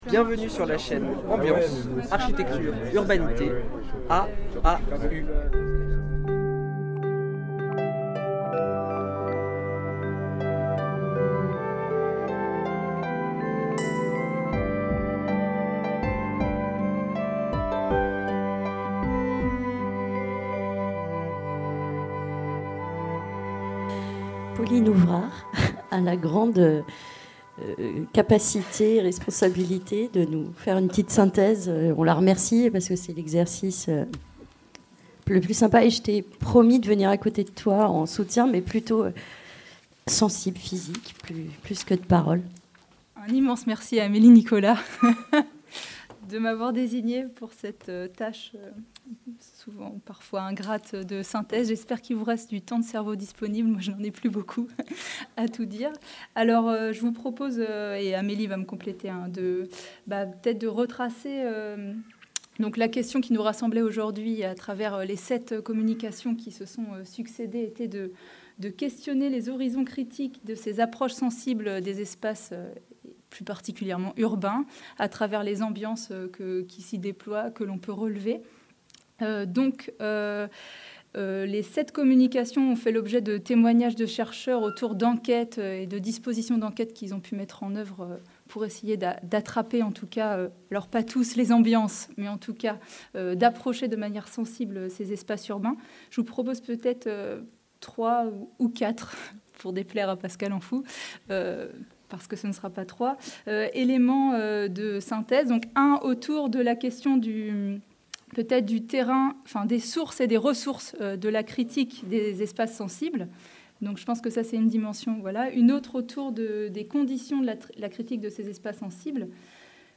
Synthèse de la journée et débat collectif